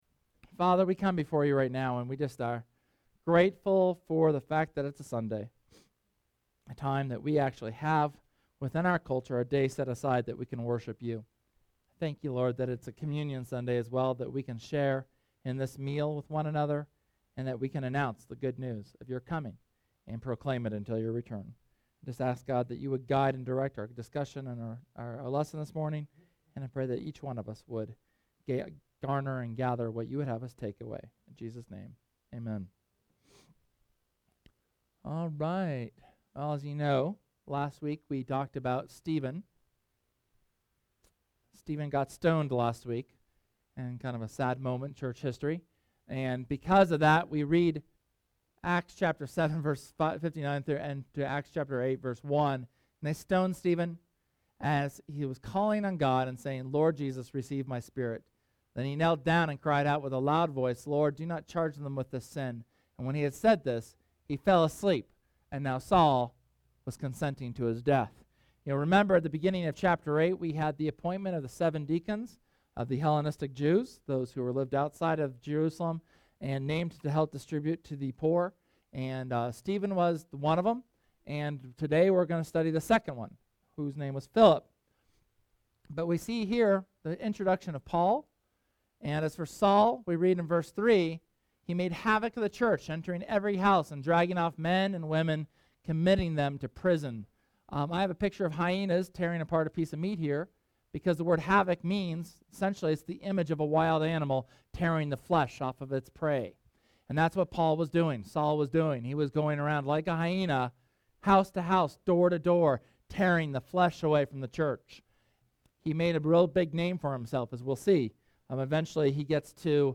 Sermon from Sunday, June 9th on mission of Philip in Samaria in Acts 8. The passage challenges some of our beliefs and calls us to a higher lever of engagement with the Good News.